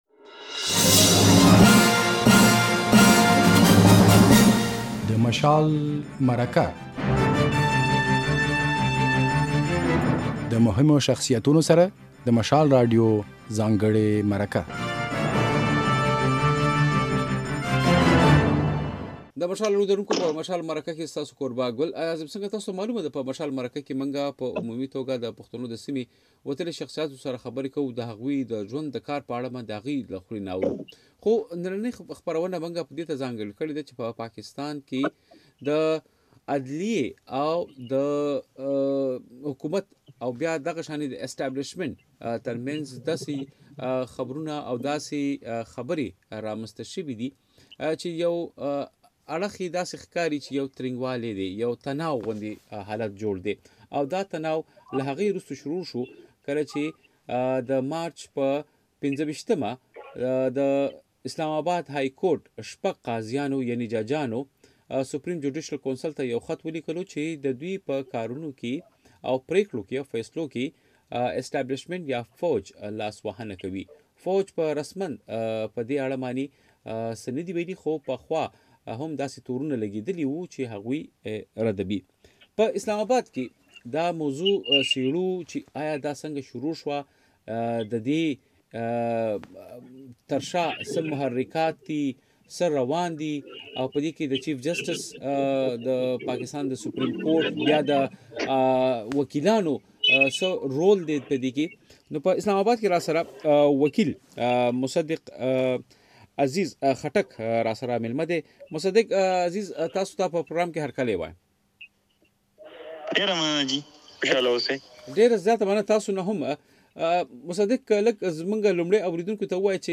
ده زیاته کړه چې له عدليي سره دا يوه ښه موقع ده چې د ادارو د کار چوکاټ معلوم کړي. بشپړه مرکه واورئ.